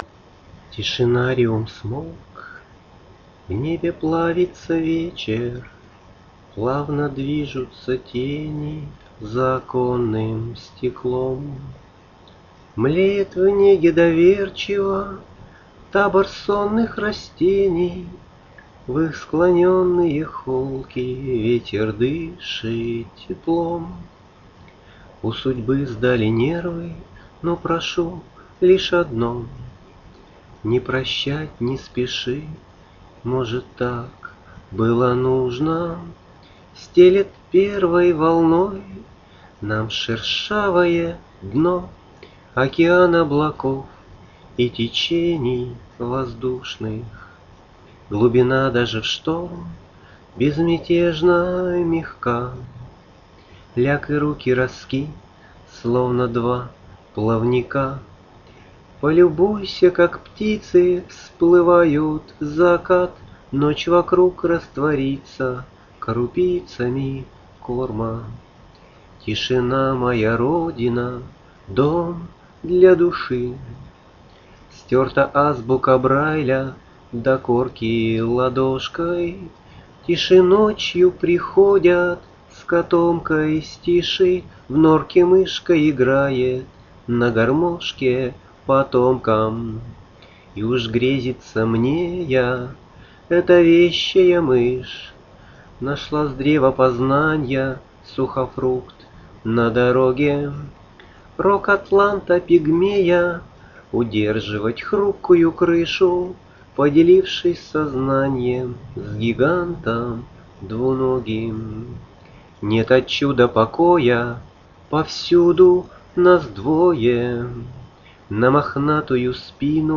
стишотворение
Когда ты поешь, у тебя так преображается голос!